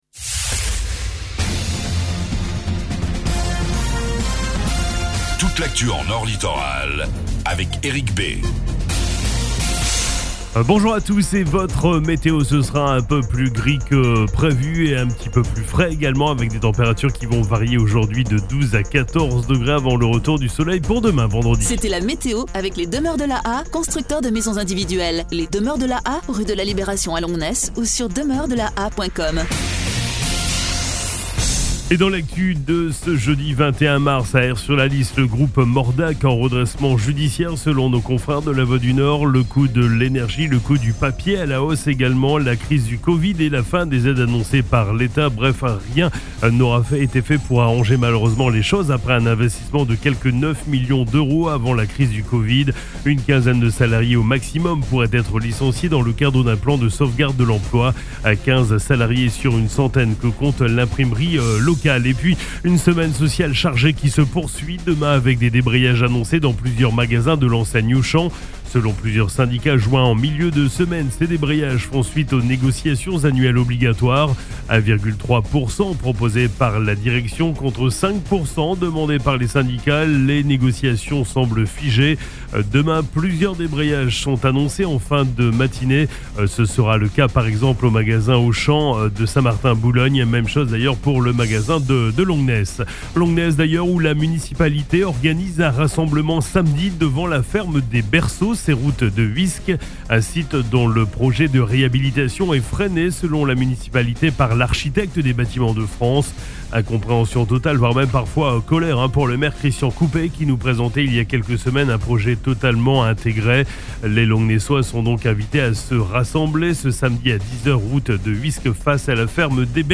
FLASH 21 MARS 24